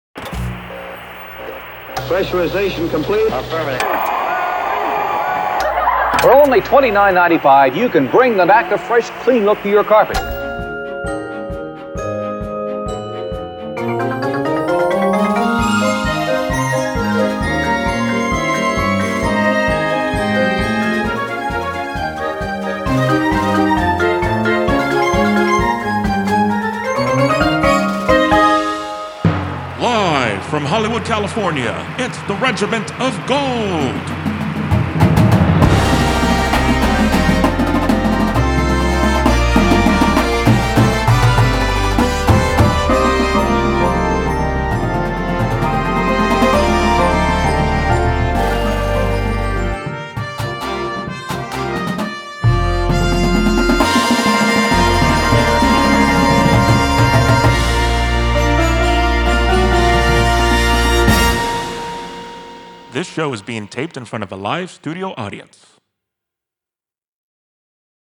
Marching Band Shows
and the swing of a big band sound